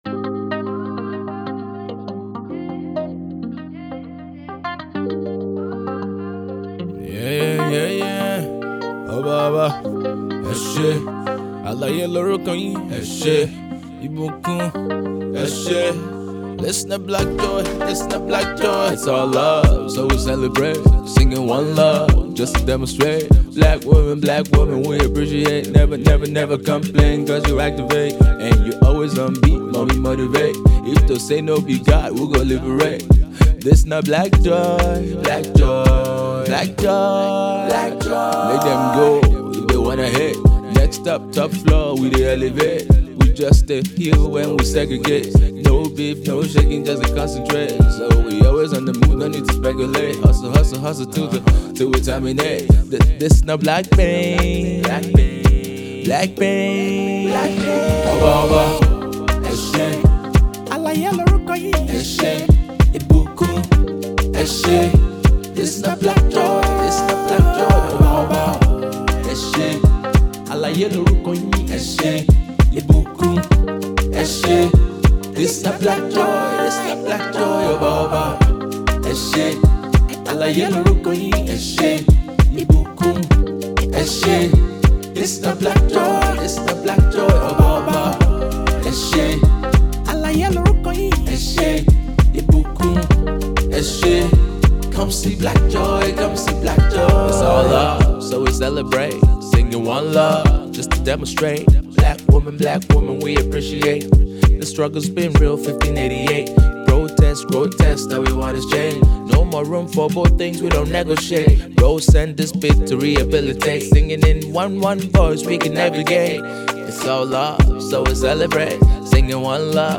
Lyrical + Meaningful + AfroHipHop + Full Of Energy